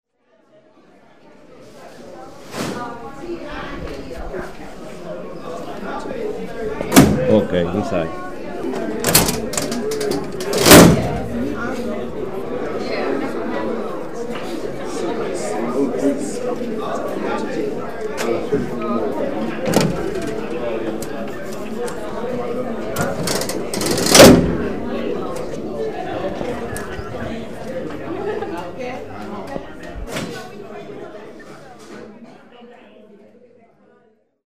sometimes sound like torture chambers, poll workers, pollsters, polling places, angry lawyers, writs of law, writs of ballots, writs speeches, buttons, placards, signs & signs.
lever-machine-mix_mono_short.mp3